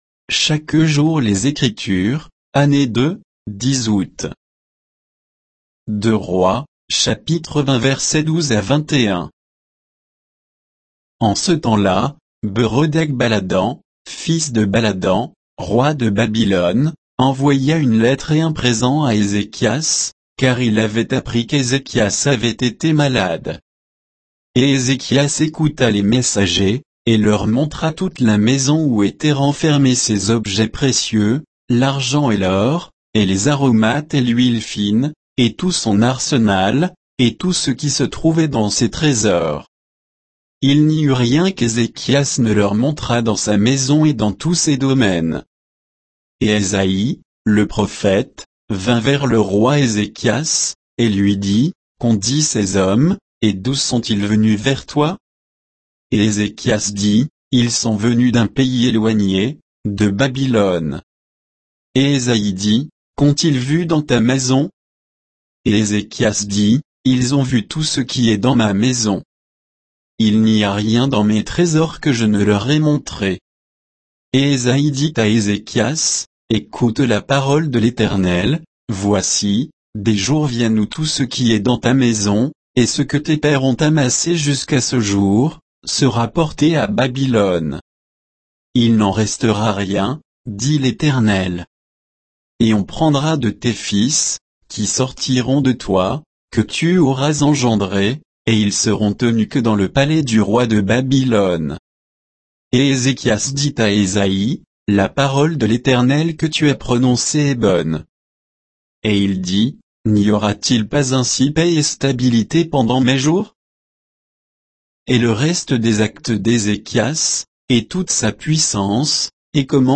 Méditation quoditienne de Chaque jour les Écritures sur 2 Rois 20